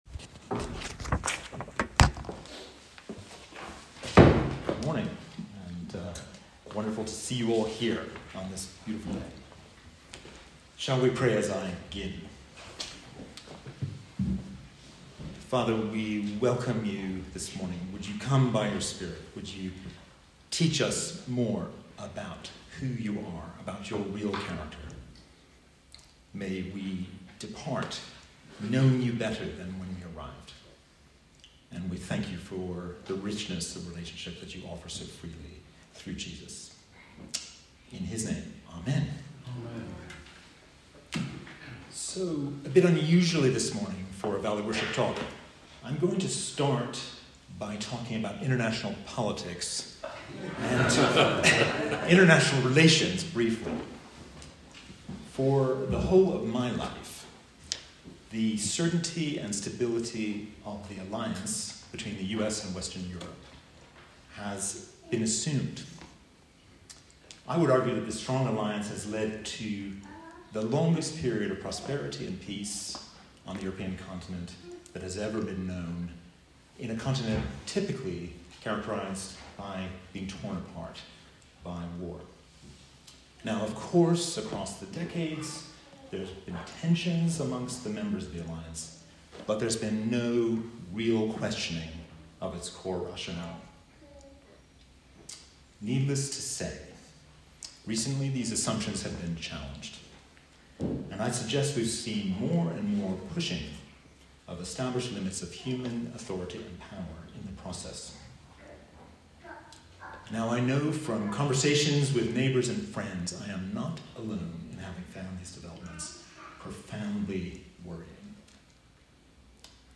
On a glorious Sunday morning, with the sun streaming through the windows of St Swithun’s, a good congregation gathered for Valley Worship yesterday in Martyr Worthy.